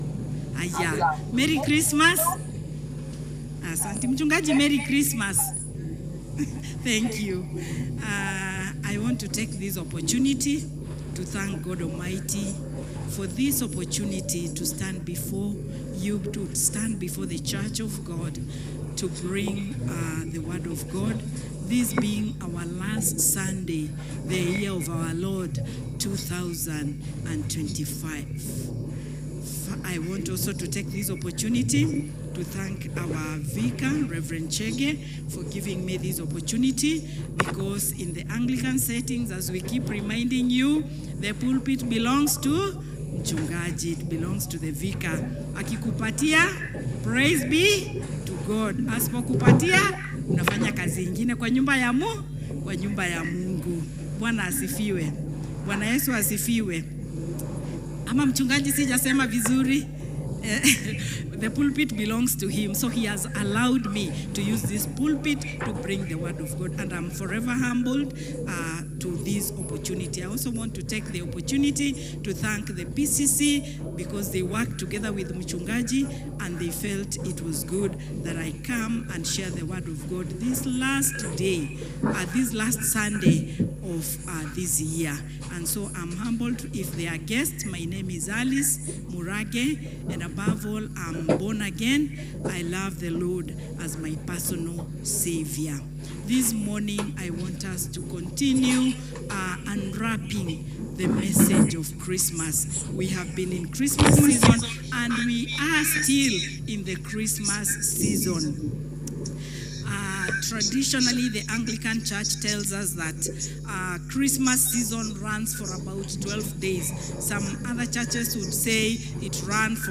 English Service
Sermon Message